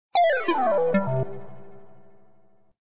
8_Descending Gamey Blip.m4a